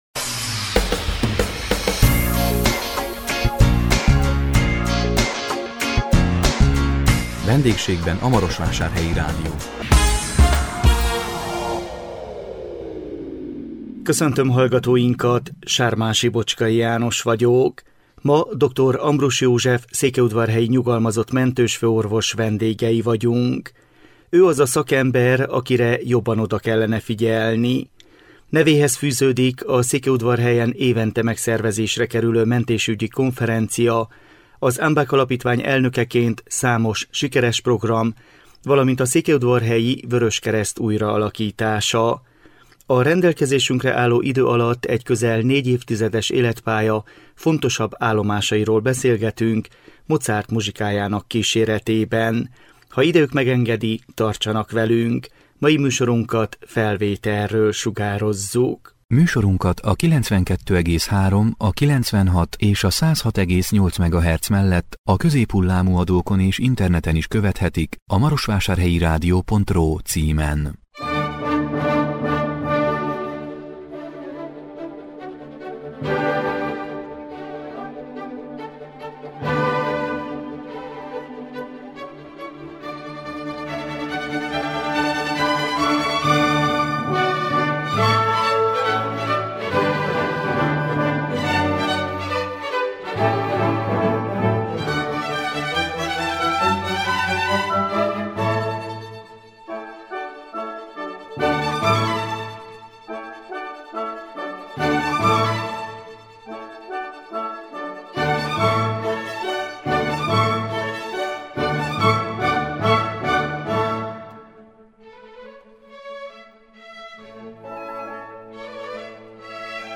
A rendelkezésünkre álló idő alatt egy közel négy évtizedes életpálya fontosabb állomásairól beszélgetünk.
Műsorunk a 2022 szeptember 29-i műsor ismétlése!